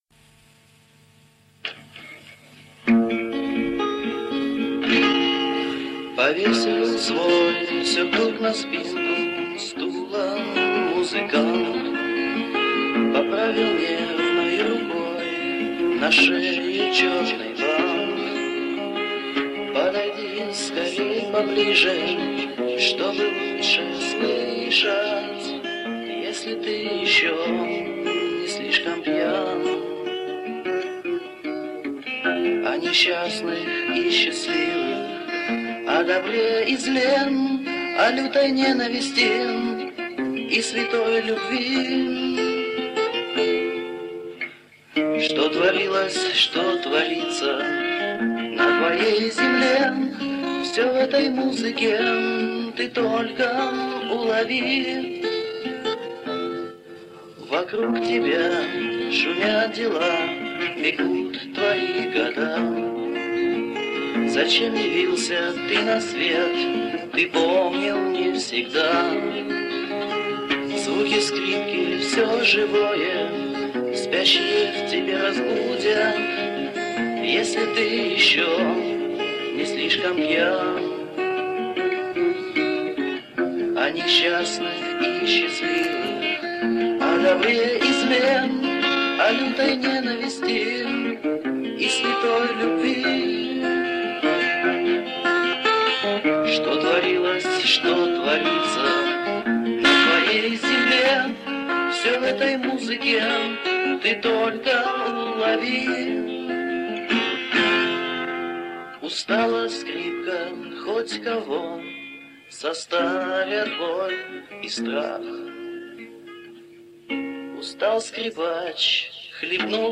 Восстановлено с осыпающейся бобины.
вокал, гитара